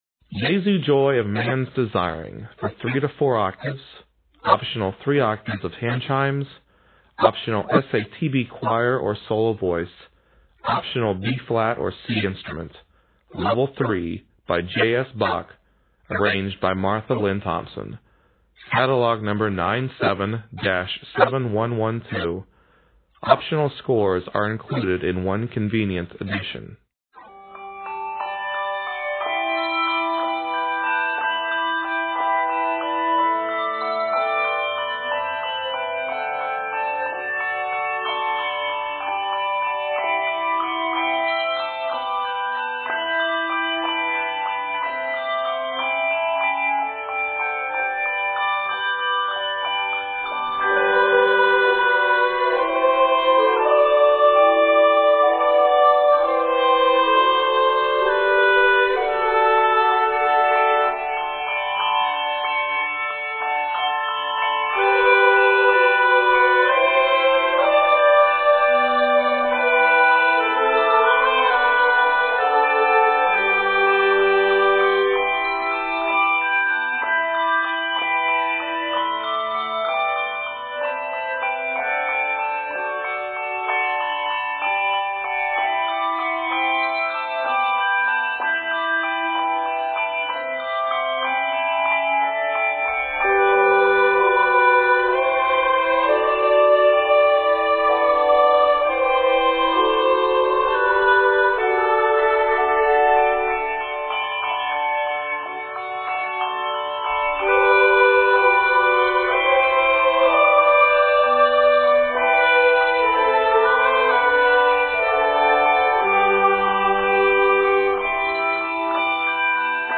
chorale
Set in G Major, measures total 71.